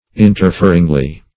\In`ter*fer"ing*ly\